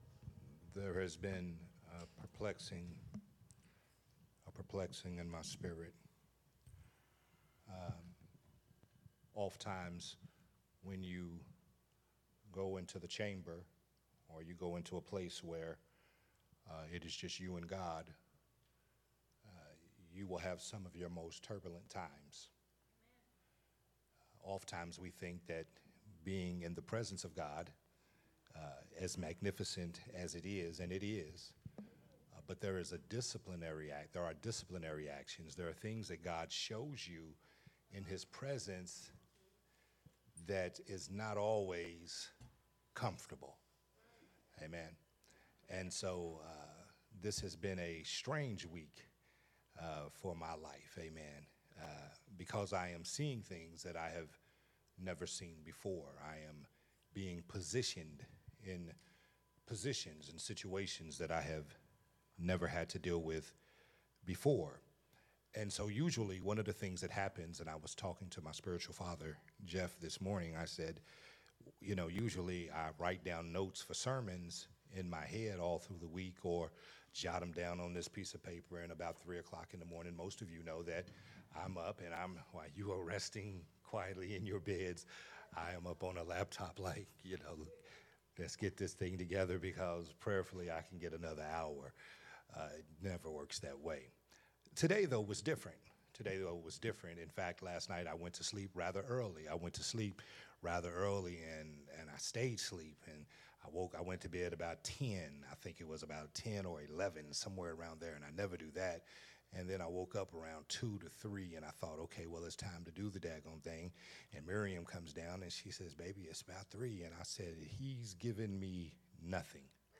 a Sunday Morning Worship sermon
recorded at Unity Worship Center on Sunday